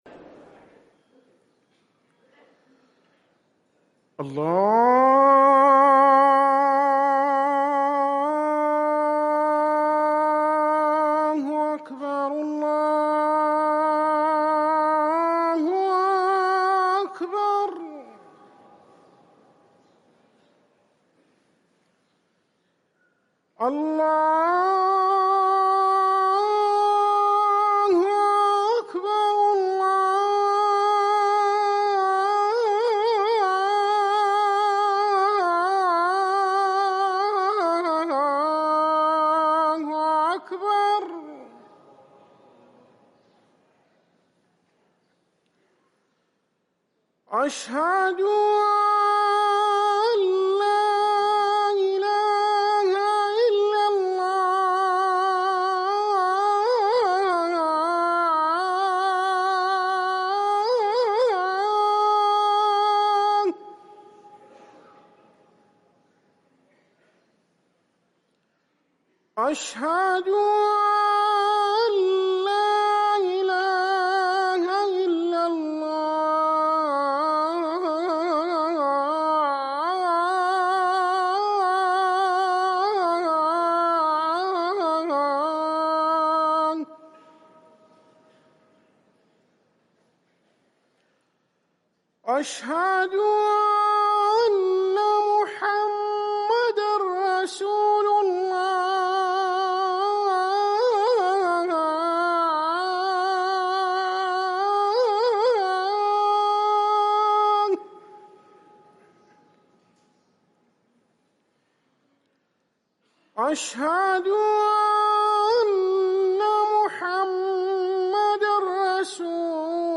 اذان الفجر